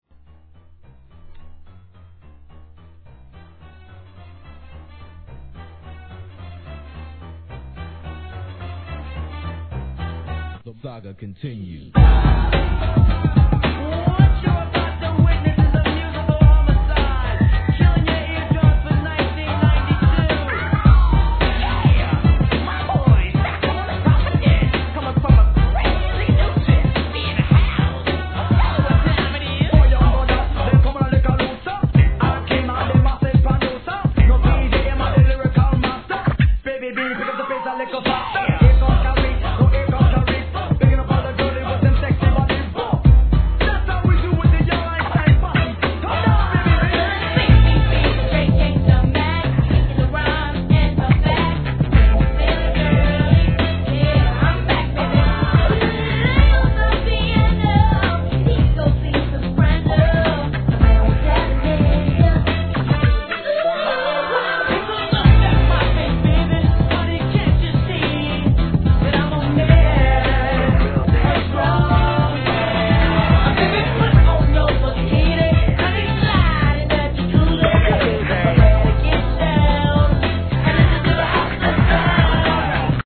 HIP HOP/R&B
若さ有るテンションに見事なコーラス・ワーク!!